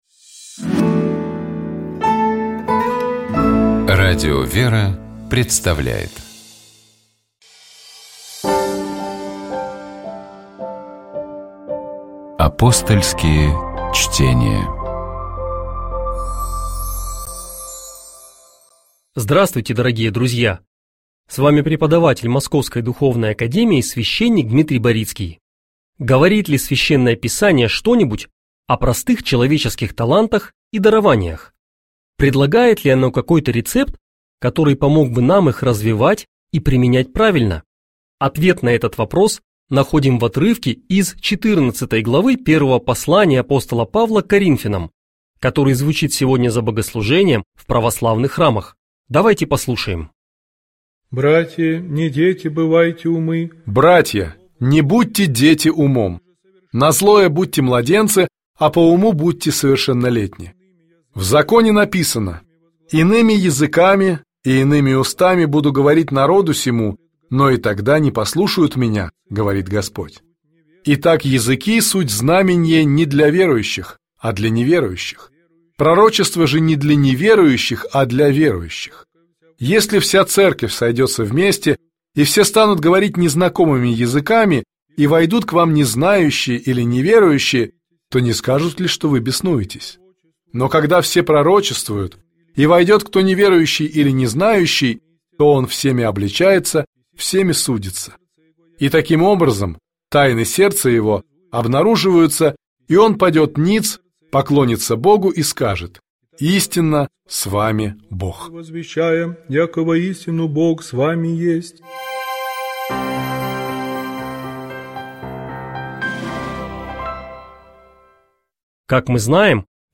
Псалом 120. Богослужебные чтения - Радио ВЕРА